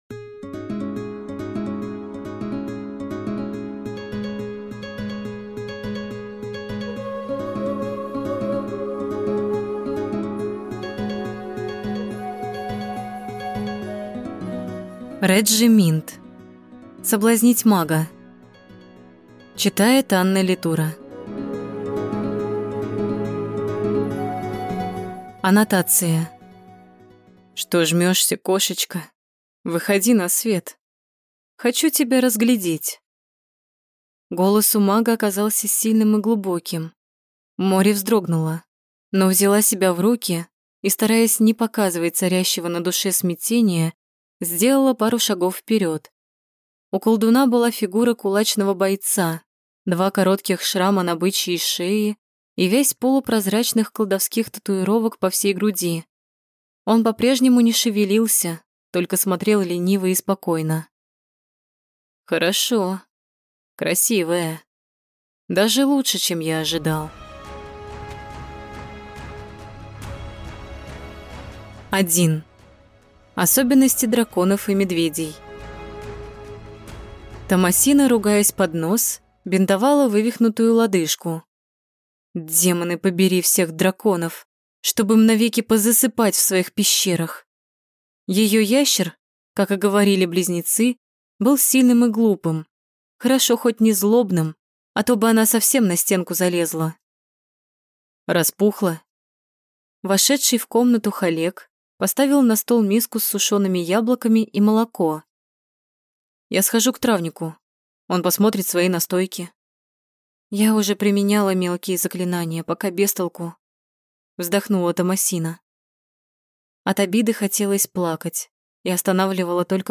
Аудиокнига Соблазнить мага | Библиотека аудиокниг